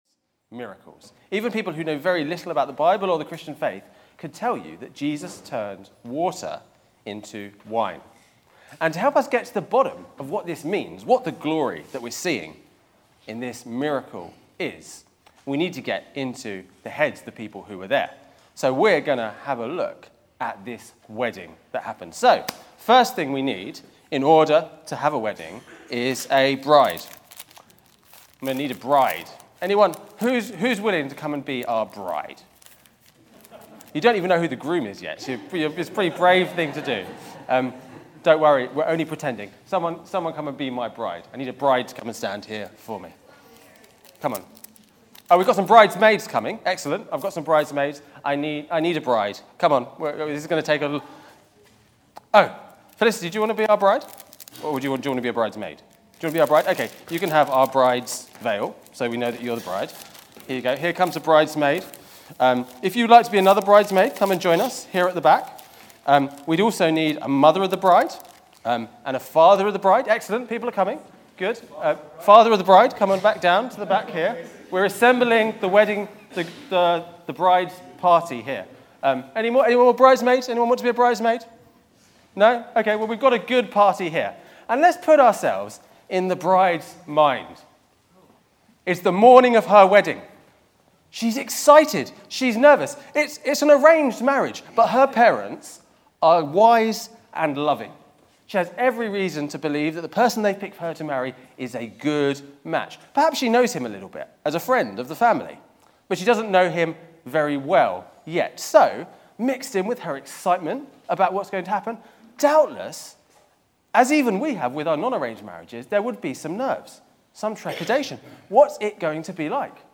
Media for Wargrave 9.45am
Theme: Sermon